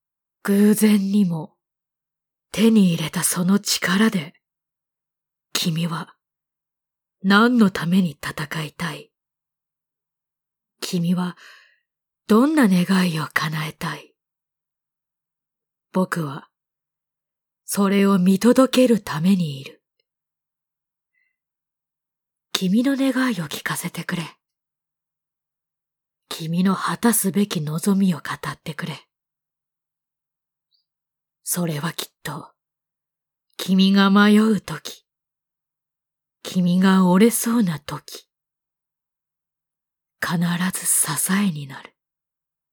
抑揚がない話し方や立ち振る舞い、